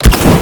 flamethrower-start-1.ogg